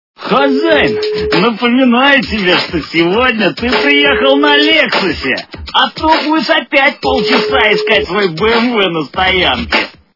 При прослушивании Голос - Хозяин напоменаю тебе сегодня ты приехал на Лексусе качество понижено и присутствуют гудки.